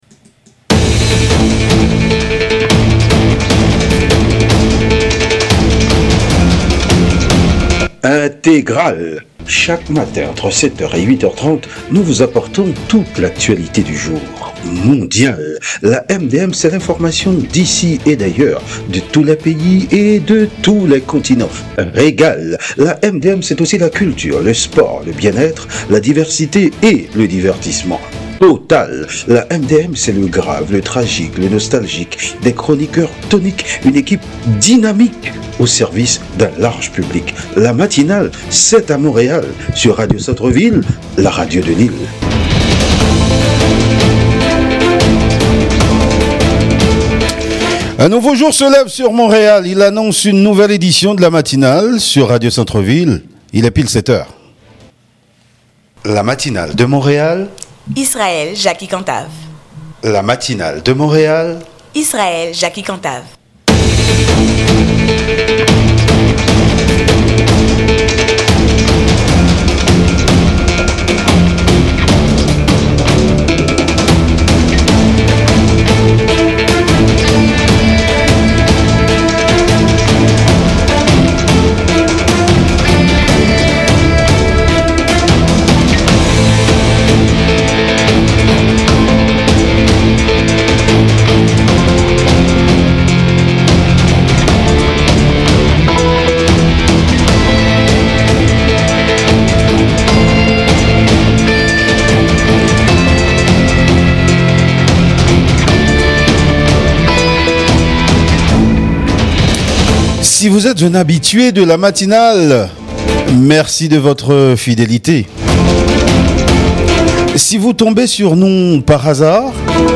Entrevue et Analyse